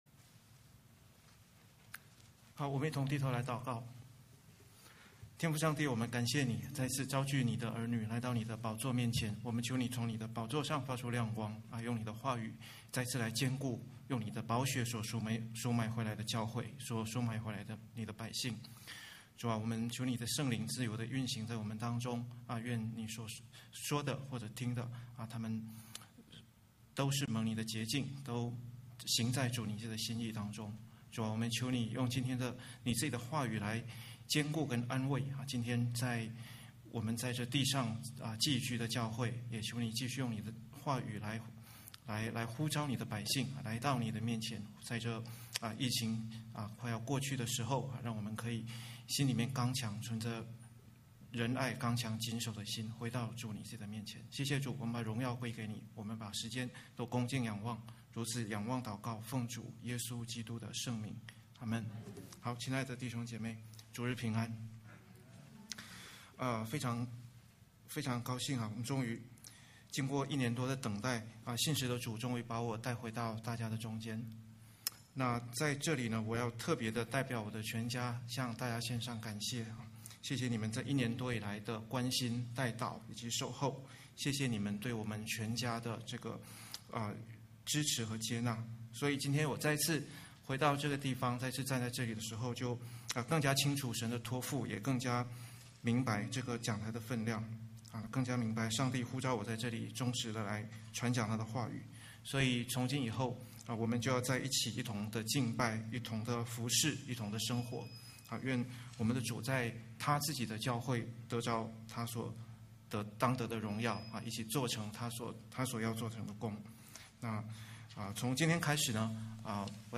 經文Scripture: 彼得前書 1:1-5 系列Series: 主日證道